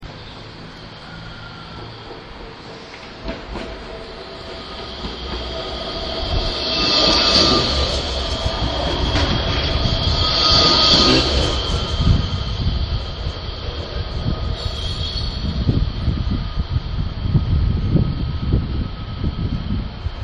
Tram drives past